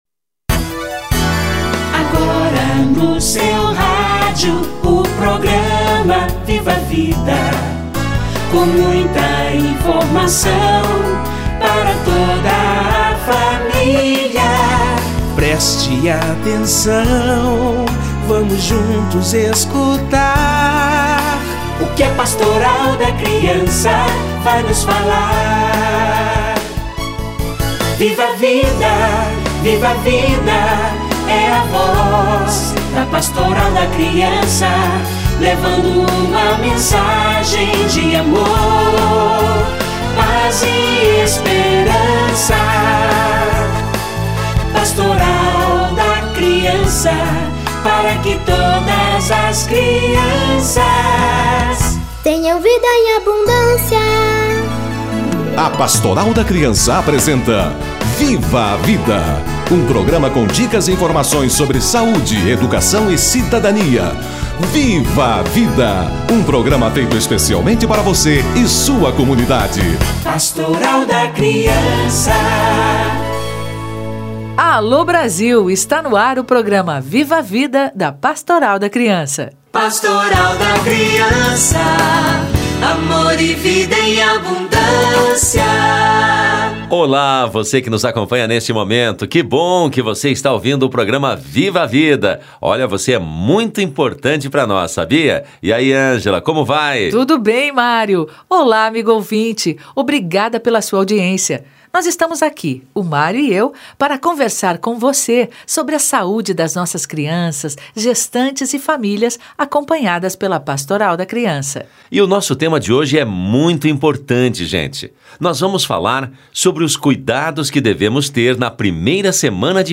Cuidados na primeira semana de vida - Entrevista